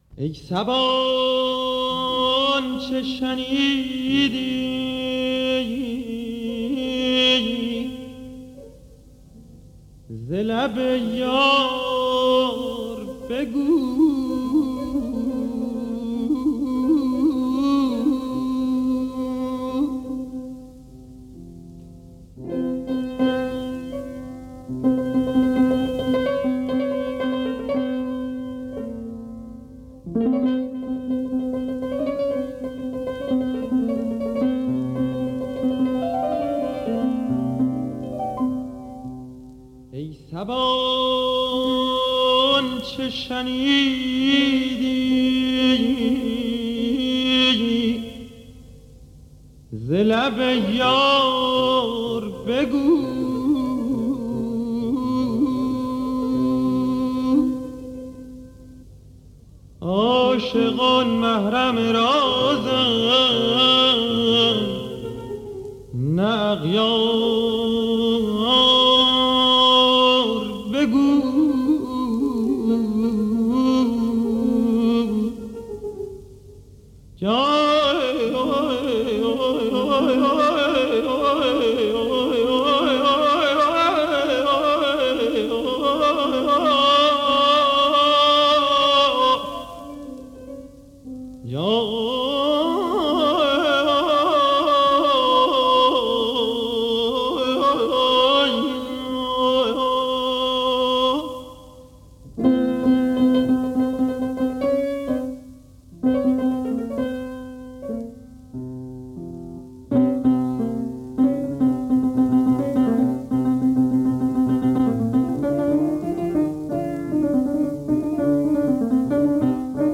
ペルシャ音楽
ペルシャのコブシ、タハリールを操る歌手の中でも
圧倒的な存在感を持った歌声が強烈に、時に神々しくも聴こえてくる傑作です！